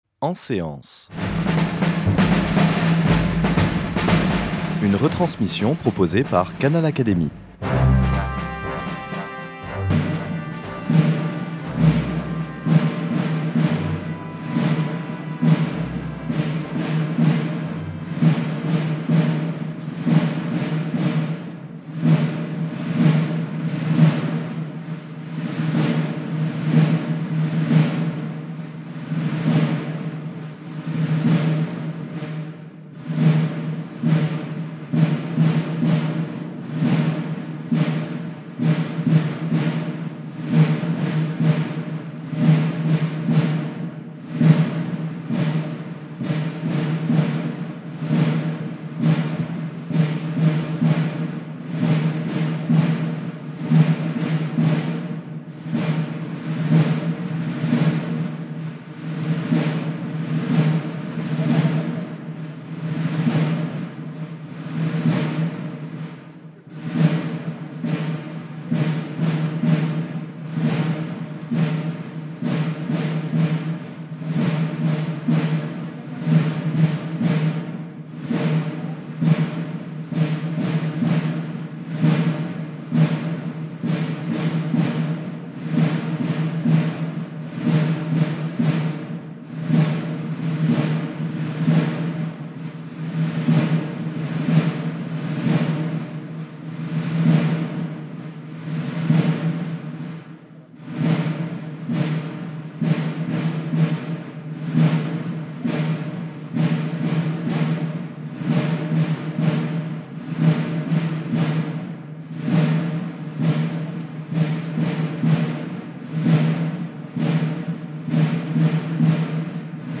Cérémonie d’installation de Mario Monti à l’Académie des sciences morales et politiques
Le discours d’installation du « professore », économiste renommé qui siégea longtemps à la Commission européenne, a été prononcé par Thierry de Montbrial, président de l’Ifri et membre de l’Institut.